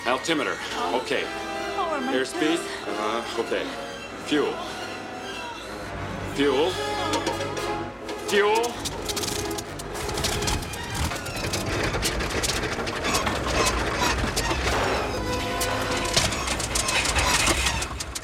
The sound of the plane's engines failing as they run out of fuel is the same as that of the erratic hyperdrive engine of the Millennium Falcon in Star Wars: The Empire Strikes Back.
plane engine
plane-engine.mp3